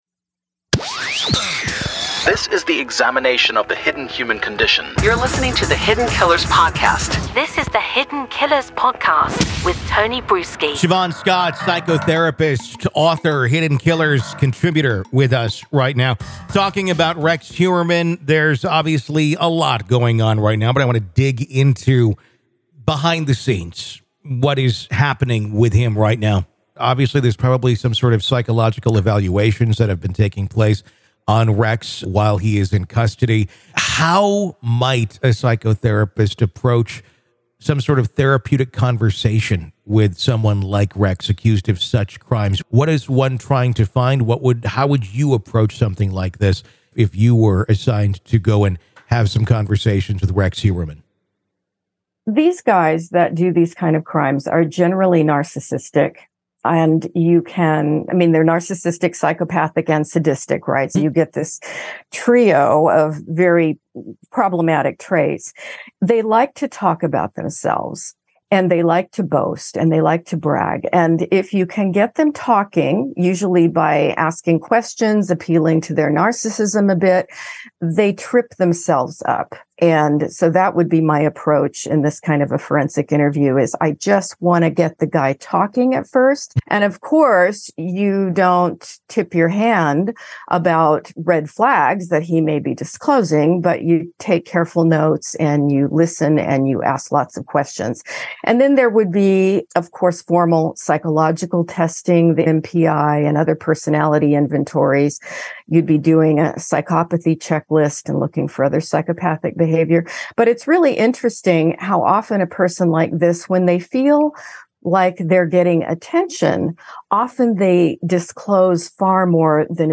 True Crime News & Commentary